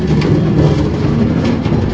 minecart